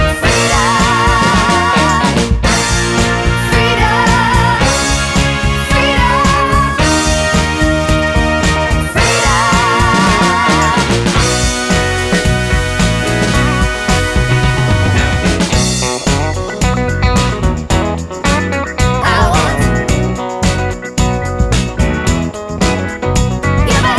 no Backing Vocals Soul / Motown 2:19 Buy £1.50